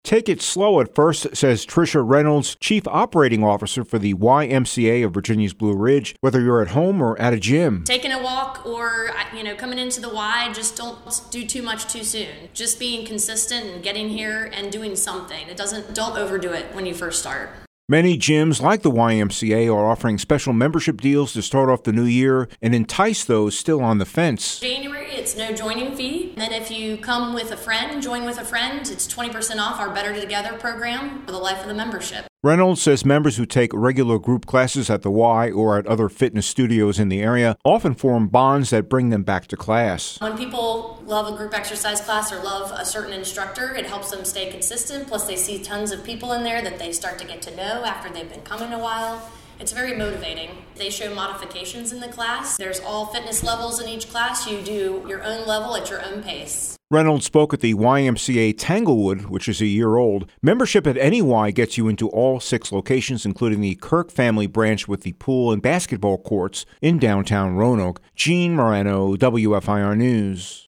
A YMCA officer locally says people at just about any level of fitness can make a plan